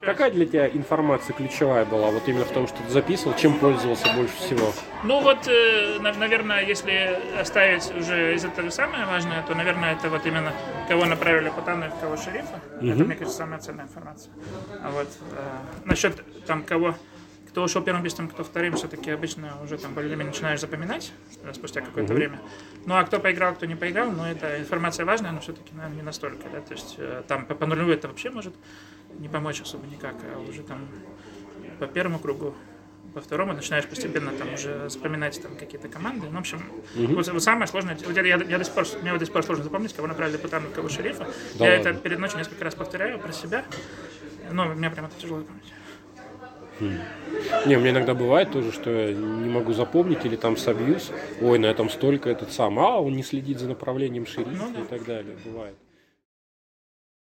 Фрагмент интервью:
tt_interview_take4.mp3